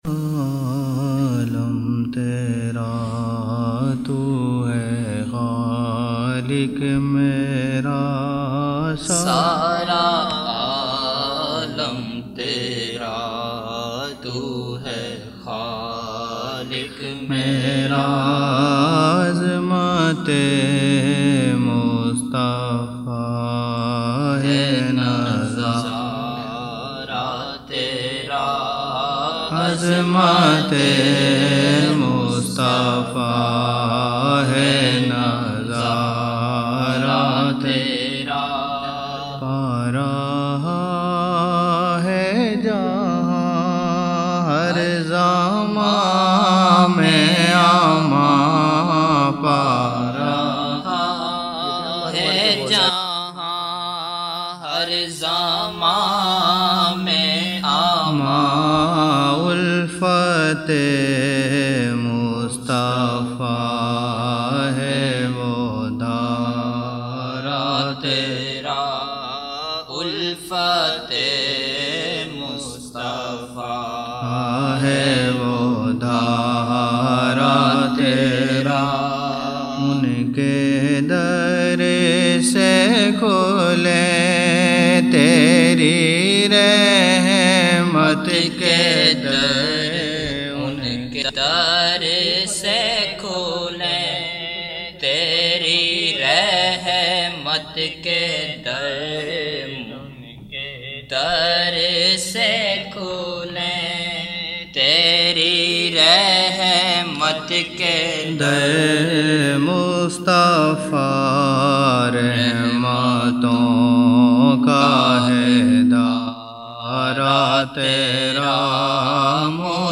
6 November 1999 - Zohar mehfil (8 Shaban 1420)
Naat shareef